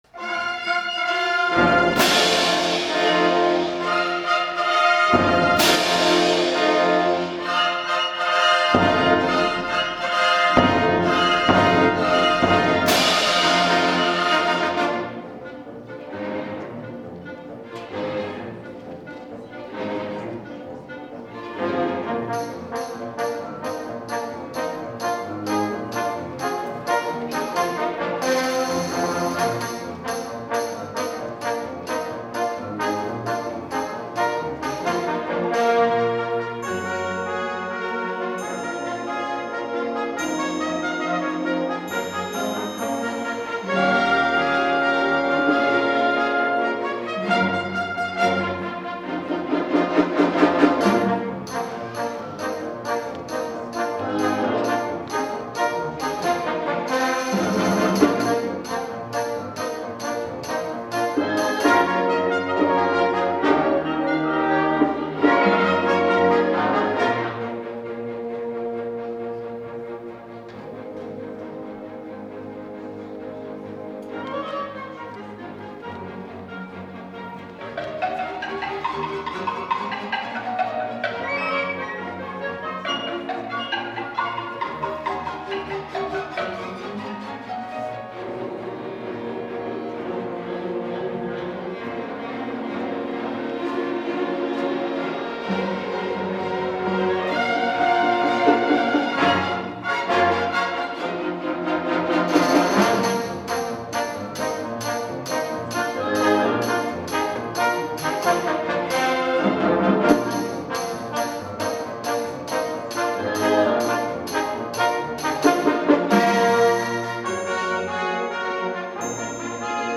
for Orchestra (2003)